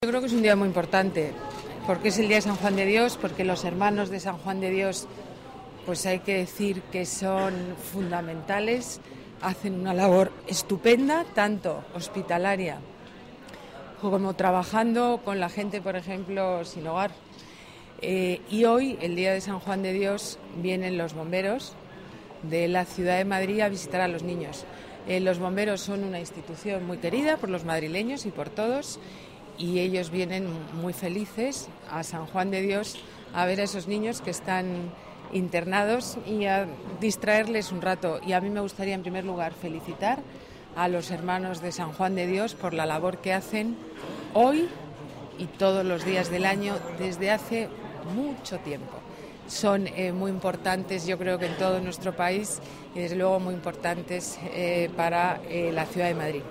Nueva ventana:Declaraciones alcaldesa Madrid, Ana Botella: visita a los niños del Hospital de San Rafael, día patrón Cuerpo Bomberos